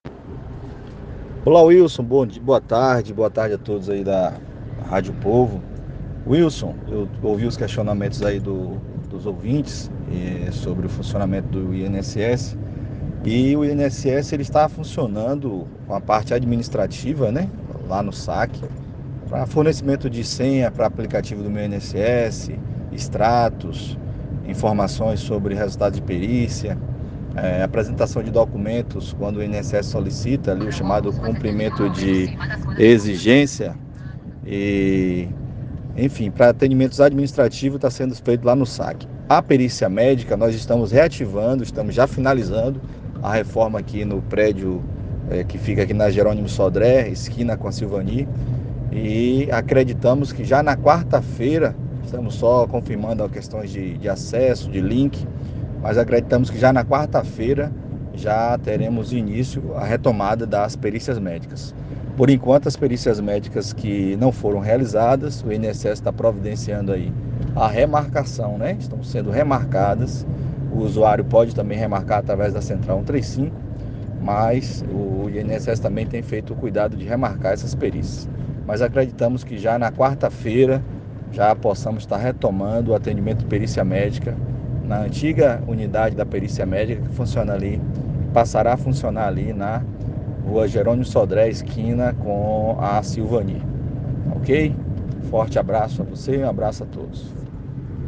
esclareceu no programa ‘Bahia Ponto a Ponto’,  da Rádio Povo FM